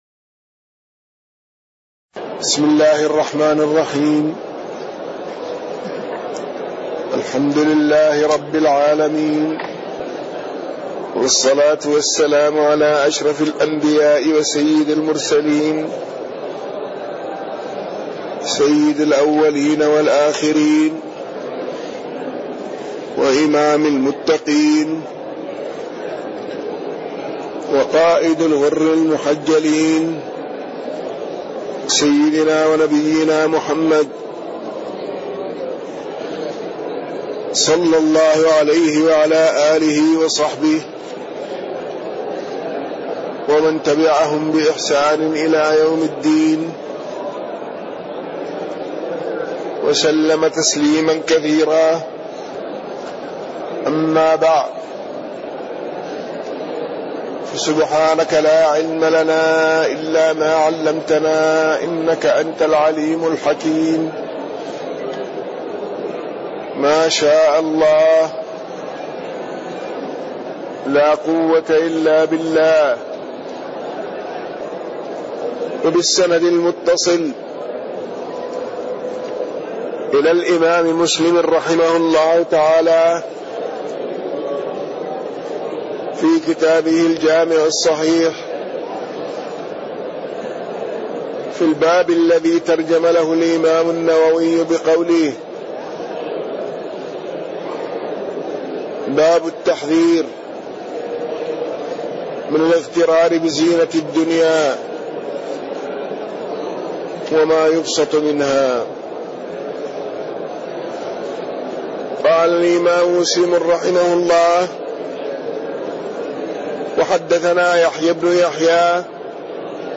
تاريخ النشر ١٣ شوال ١٤٣٢ هـ المكان: المسجد النبوي الشيخ